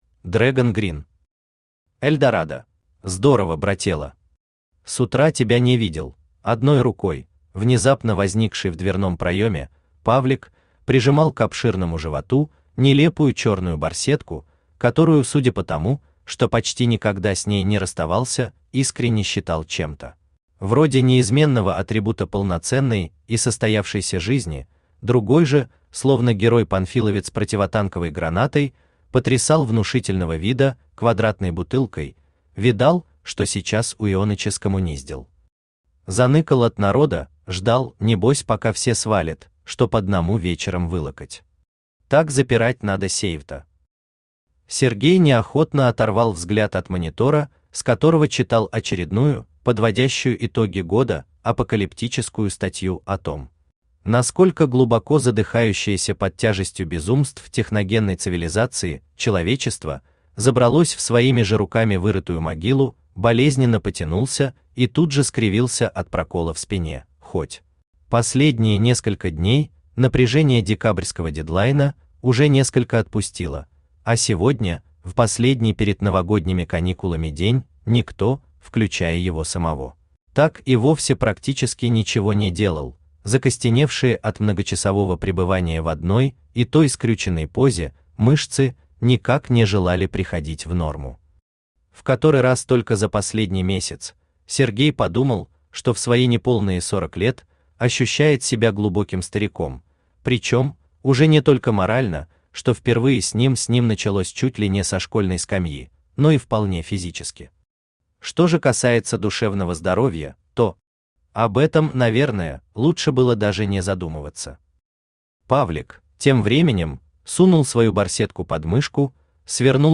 Аудиокнига Эльдорадо | Библиотека аудиокниг
Aудиокнига Эльдорадо Автор Dragon Green Читает аудиокнигу Авточтец ЛитРес.